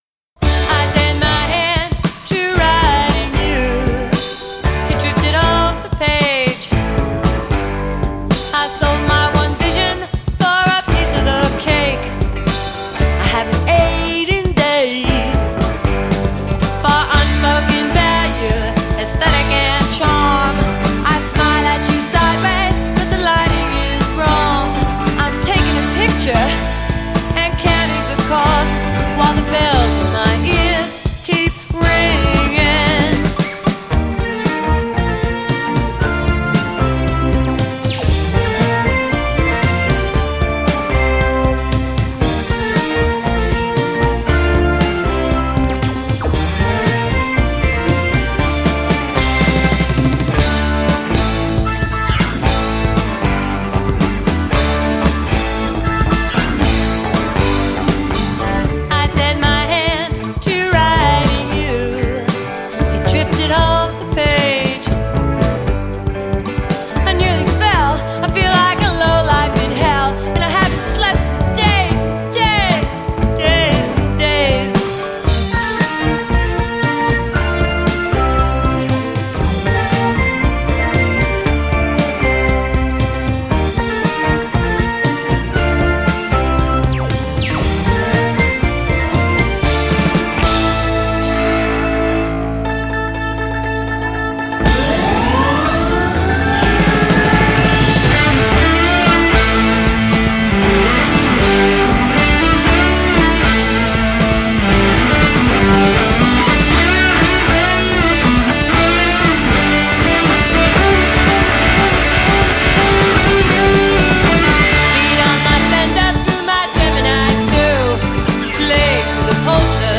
New Wave / Pop Rock / Punk Rock